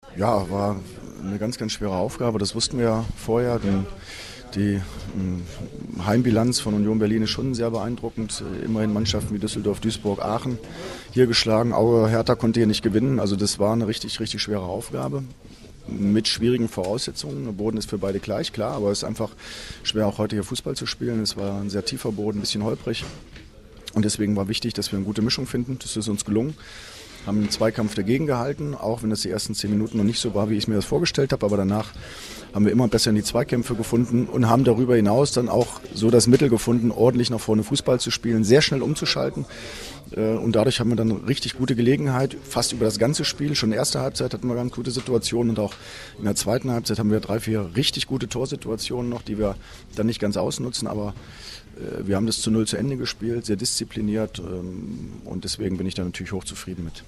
AUDIOKOMMENTAR
Sportlicher Leiter und Chef-Trainer Andre Schubert zum Spiel